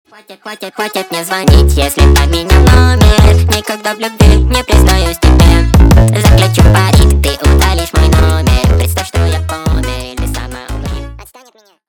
на русском на девушку веселые